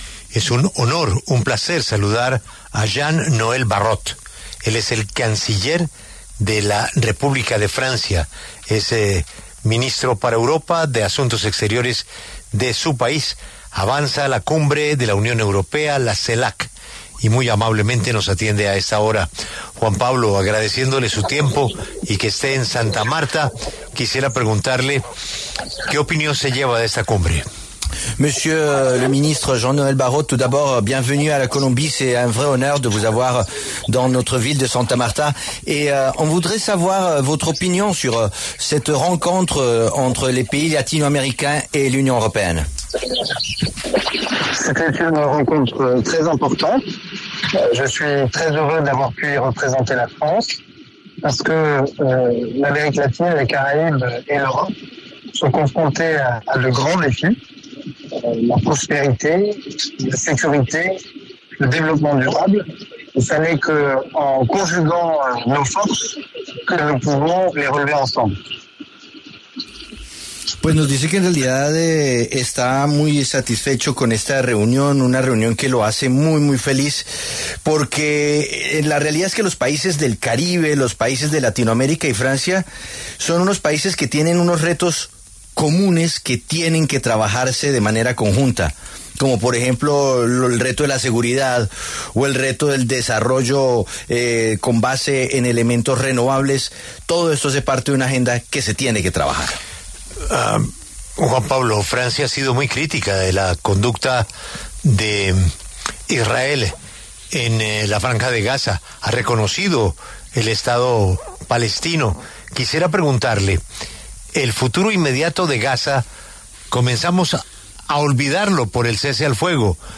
Jean-Noël Barrot, ministro de Asuntos Exteriores de Francia, conversó con La W tras la Cumbre de la CELAC-UE, que se llevó a cabo en Santa Marta.